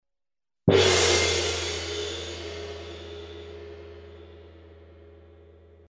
Звуки гонга
Глубокие вибрации и переливчатые обертоны подойдут для медитации, звукотерапии или создания атмосферы в творческих проектах.